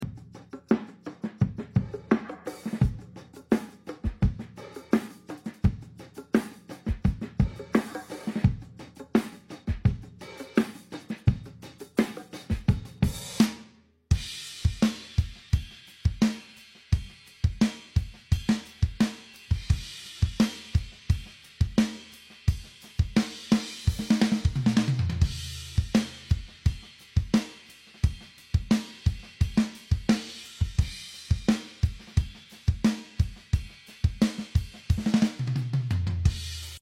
Intro was built out around a mono loop I tracked and filtered. Bongos and a couple shakers providing motion. Automated volume on the hi hat track to give the sucking effect into the full groove.